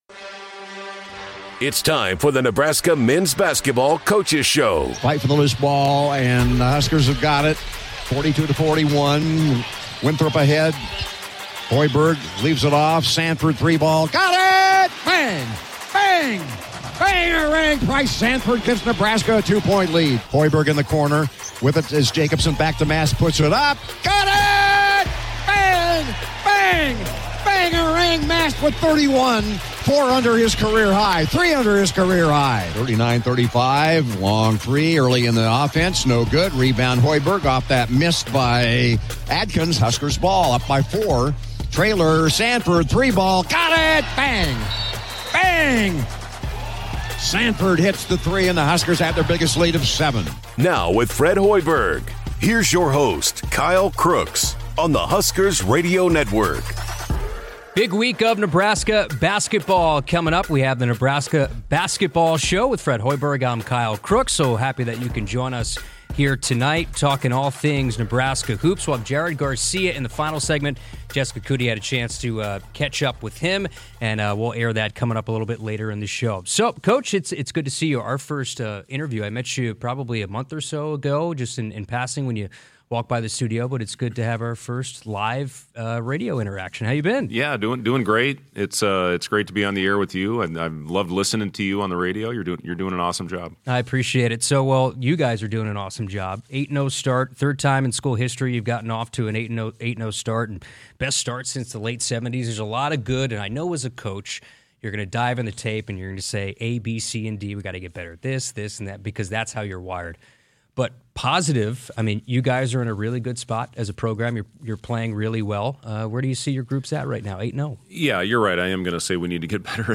chats with Nebraska head coach Fred Hoiberg